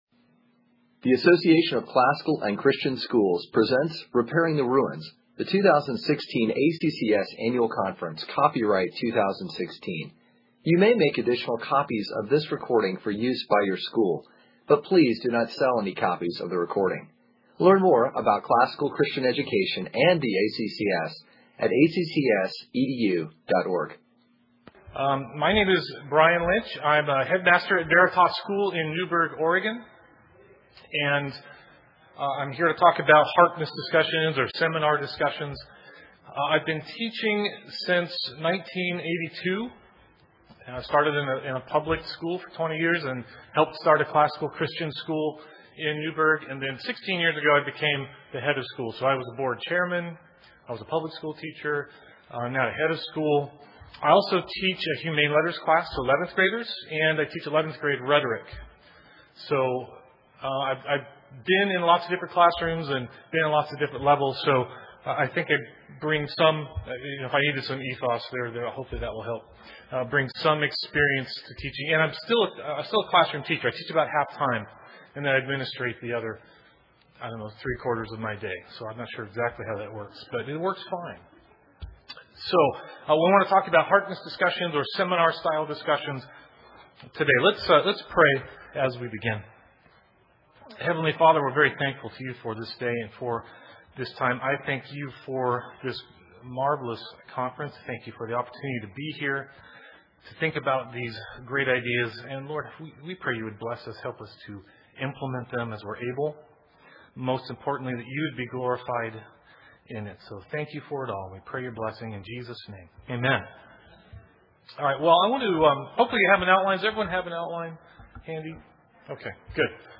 2016 Workshop Talk | 1:04:03 | All Grade Levels, Literature, Rhetoric & Composition